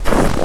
STEPS Snow, Walk 11.wav